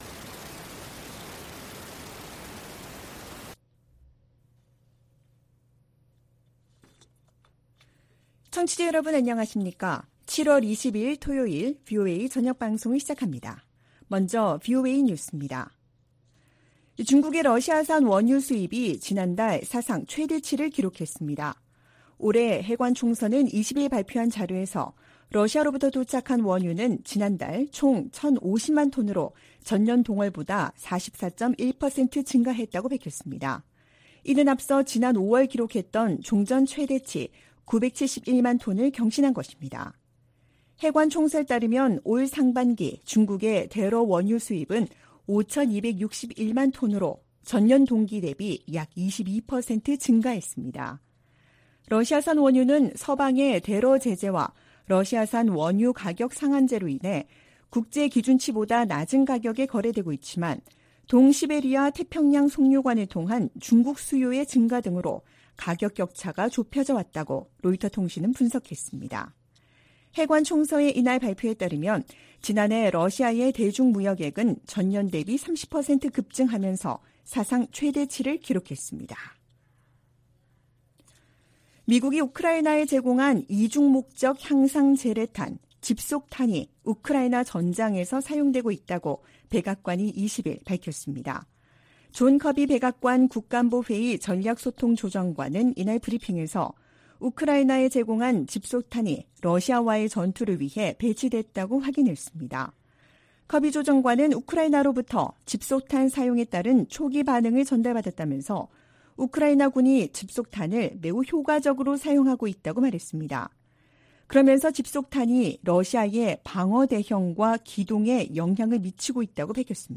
VOA 한국어 '출발 뉴스 쇼', 2023년 7월 22일 방송입니다. 백악관은 월북 미군 병사의 안전과 소재 파악에 최선을 다하고 있지만 현재 발표할 만한 정보는 없다고 밝혔습니다. 미국과 한국의 핵협의그룹(NCG)을 외교・국방 장관 참여 회의체로 격상하는 방안이 미 상원에서 추진되고 있습니다. 미 국방부가 전략핵잠수함(SSBN)의 한국 기항을 비난하며 핵무기 사용 가능성을 언급한 북한의 위협을 일축했습니다.